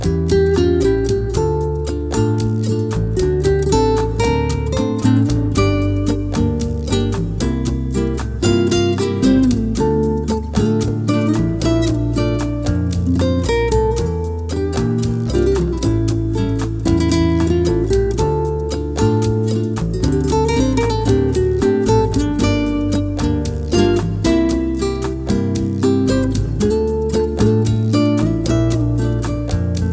• Worldwide